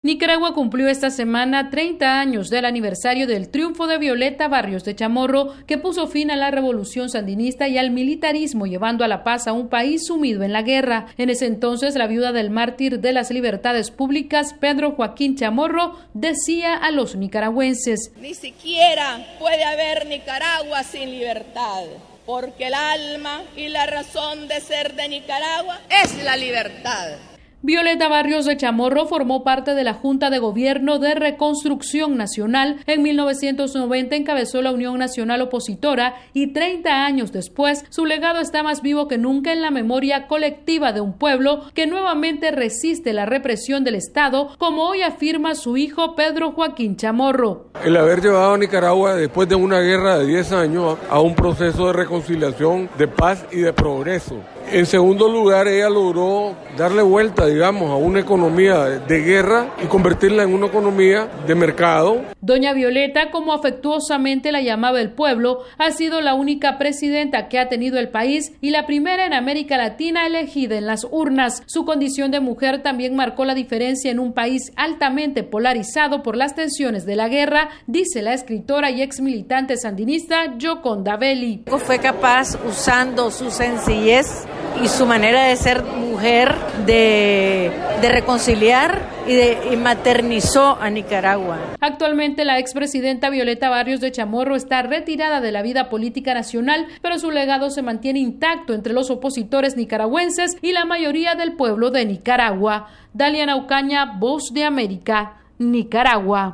Nicaragua evocó esta semana la elección y el legado de Violeta Barrios de Chamorro, la primera mujer elegida presidenta en Latinoamérica. Desde Managua informa la corresponsal de la Voz de América